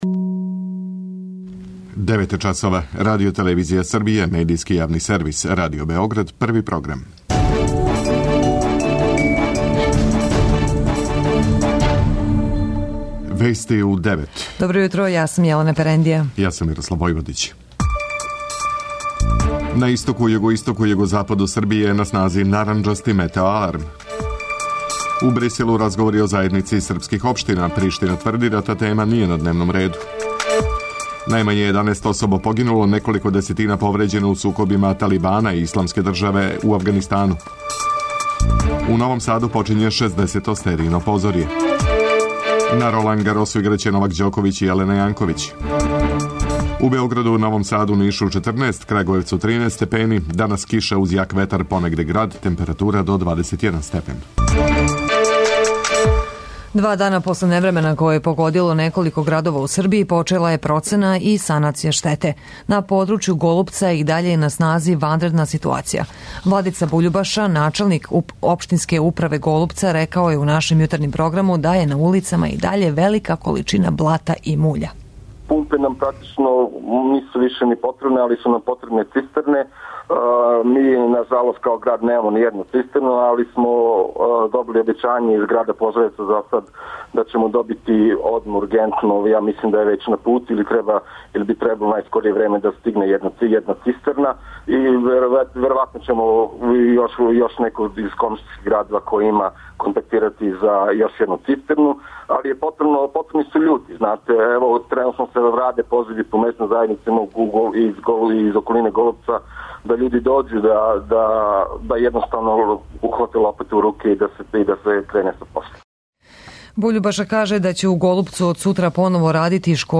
преузми : 10.64 MB Вести у 9 Autor: разни аутори Преглед најважнијиx информација из земље из света.